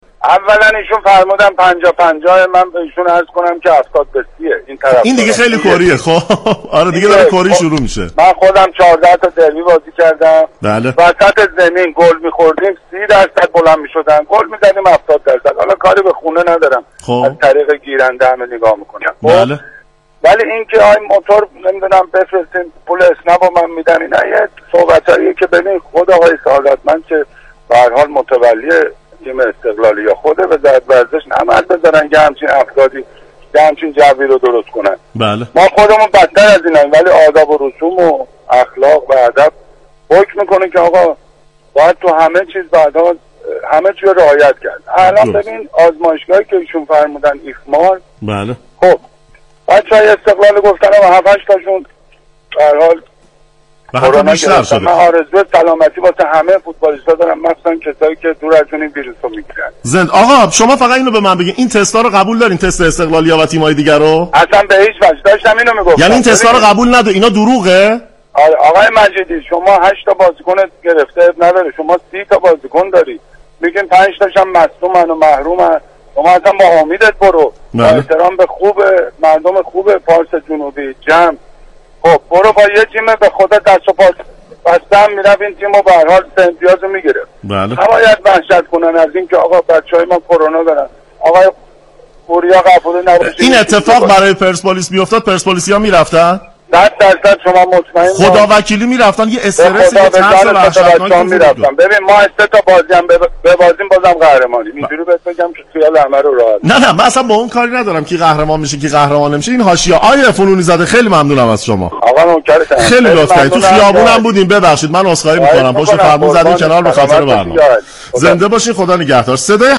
در گفتگوی تلفنی